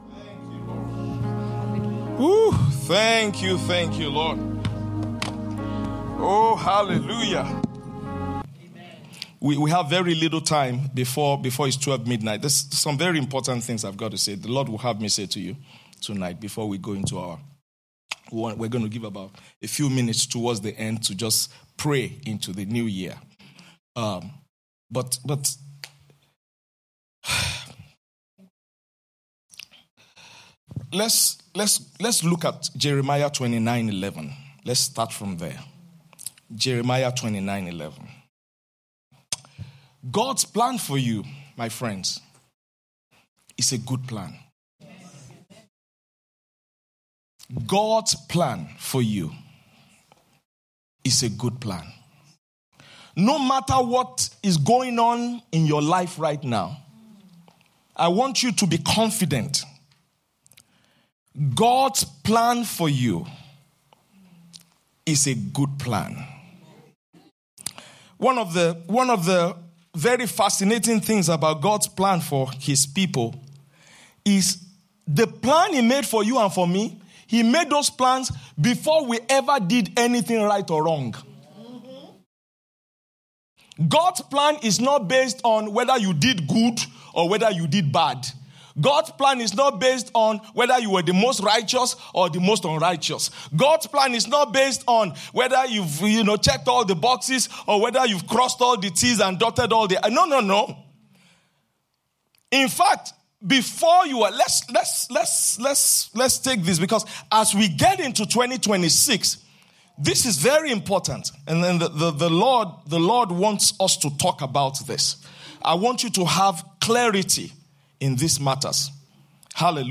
Watch Night Service 2025